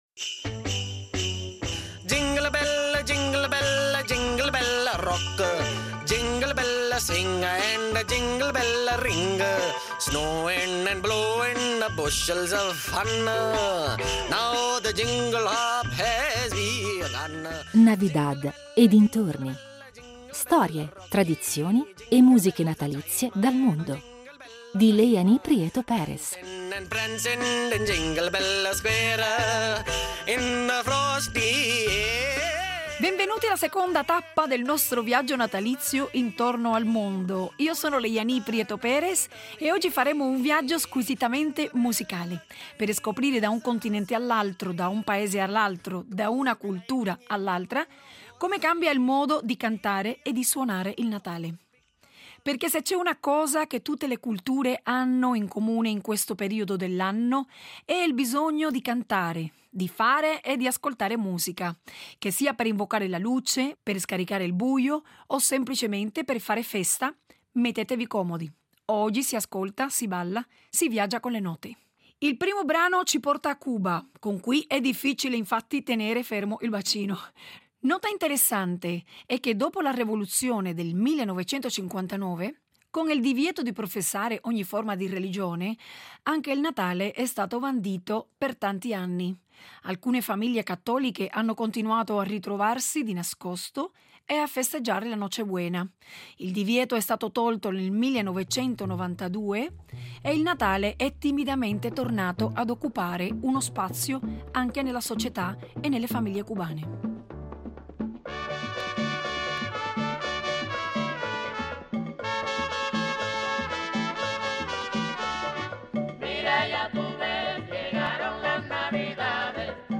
Il tutto attraverso l’ascolto delle loro musiche natalizie, spesso insolite e poco conosciute, alternate ai grandi classici che tutti conosciamo. Nella seconda puntata partiamo per un viaggio musicale attraversa la rumba cubana e i villancicos sudamericani, il gospel afroamericano e i canti ortodossi ucraini, fino al reggae caraibico e all’amapiano sudafricano.